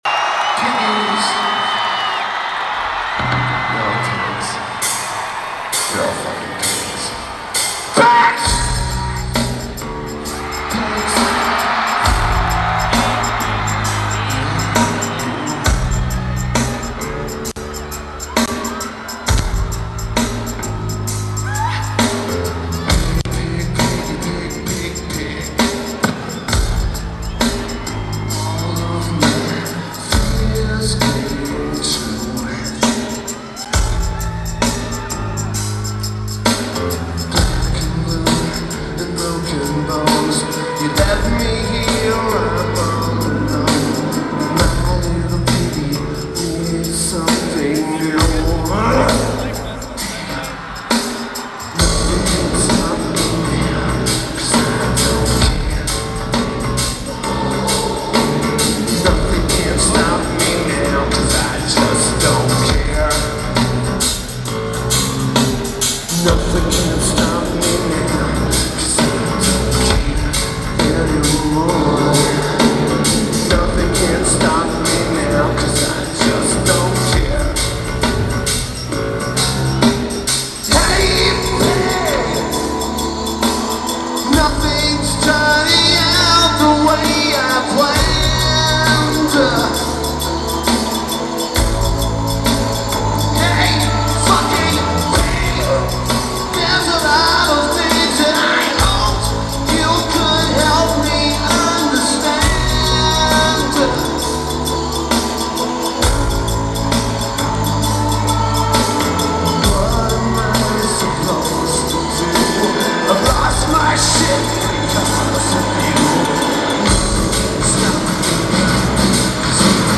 Target Center
Lineage: Audio - AUD (Sonic Studios DSM6 + Sony TCD-D7)